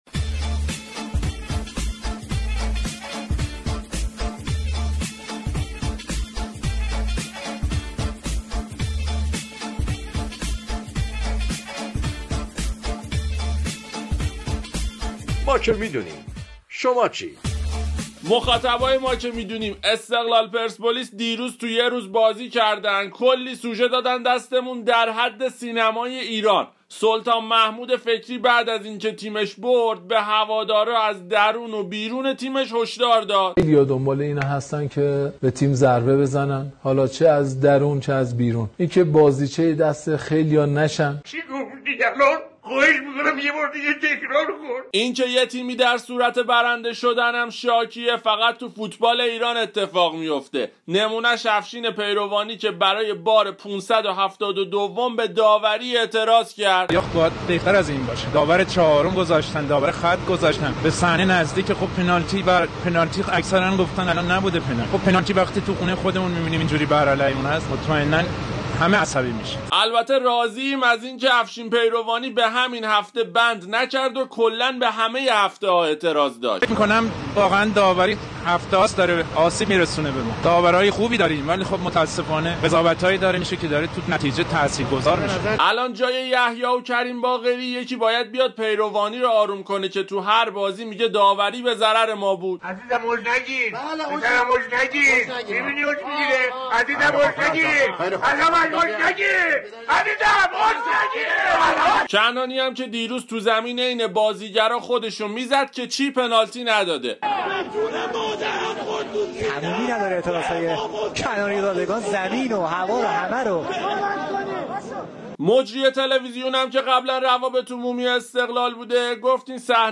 اتفاقات بازی های دیروز سرخابی ها با رقبا سوژه برنامه رادیویی ما که می دونیم شد